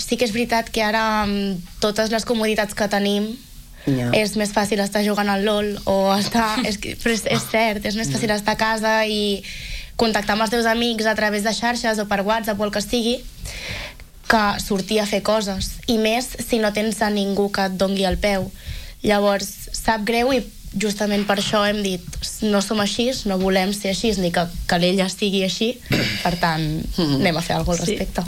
Aquest dilluns, una representació de l’associació ha passat pel matinal de RCT per explicar els seus projectes entre els que hi ha crear una marca que els identifiqui.